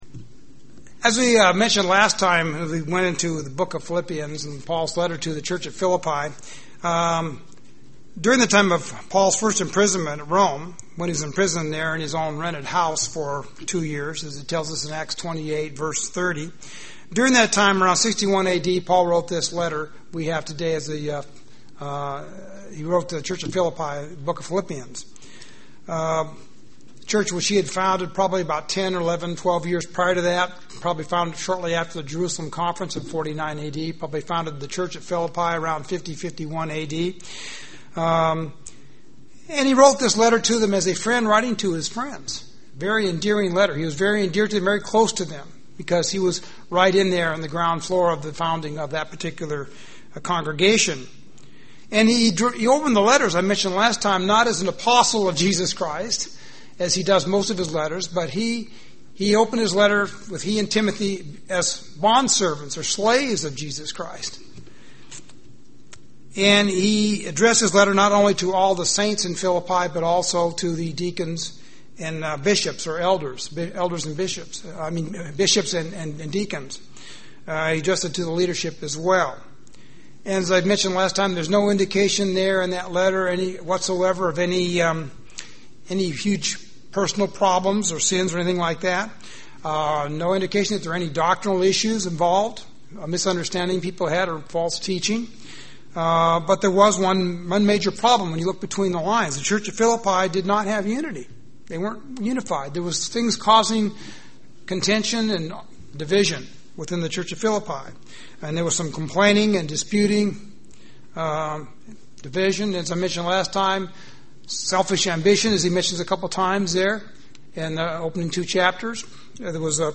This sermon continues from part one to look at the letter that Paul wrote to Philippi, looking at more of the solution.